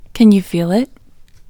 LOCATE OUT English Female 37